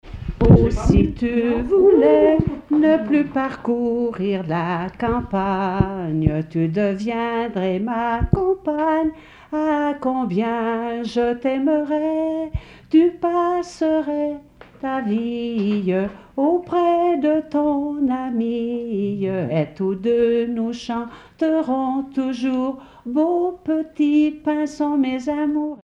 Localisation Saint-Julien-en-Genevois
Pièce musicale inédite